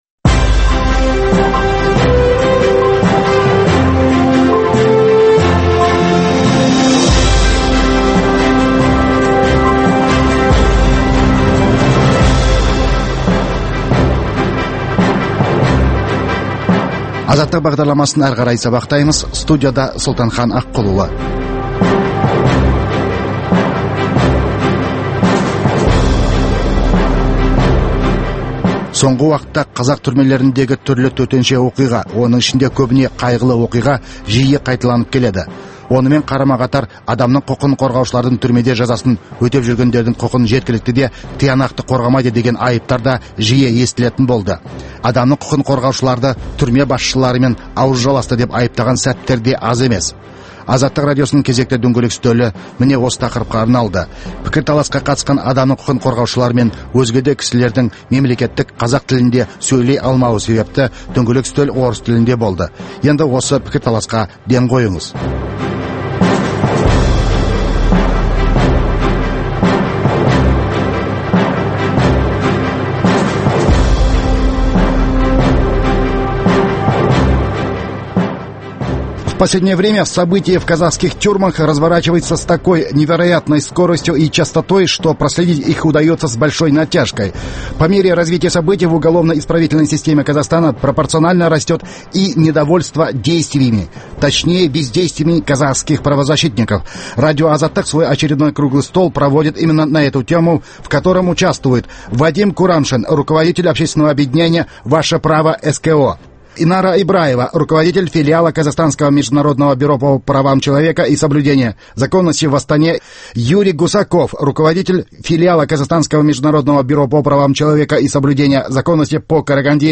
Дөңгелек үстел
Азаттық радиосының кезекті дөңгелек үстелі міне осы тақырыпқа арналады. Пікірталасқа қатысқан адам құқын қорғаушылар мен өзге де осы сала мамандары орыс тілінде сөйлегендіктен дөңгелек үстел талқылауы орысша өткен еді.